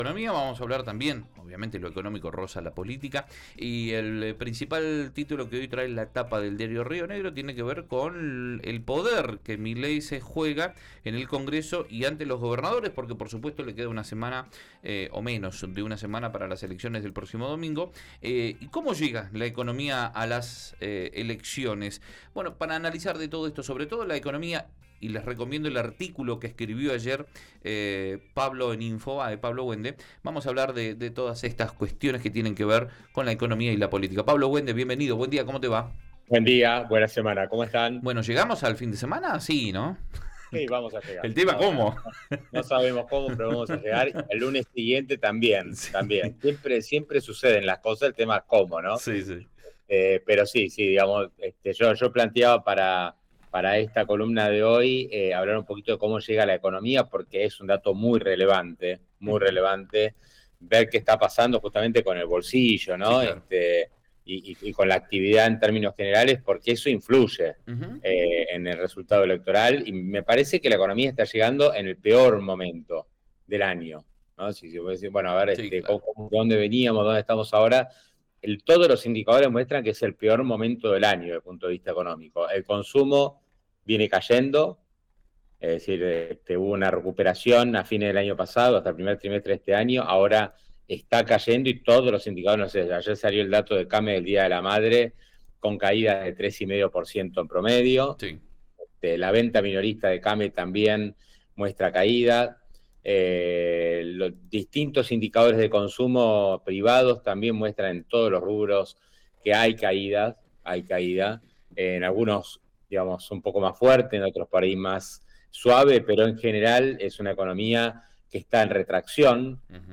columna en Río Negro Radio